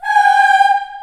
Index of /90_sSampleCDs/USB Soundscan vol.28 - Choir Acoustic & Synth [AKAI] 1CD/Partition A/06-CHILD EHS